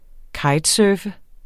Udtale [ ˈkɑjdˌsœːfə ]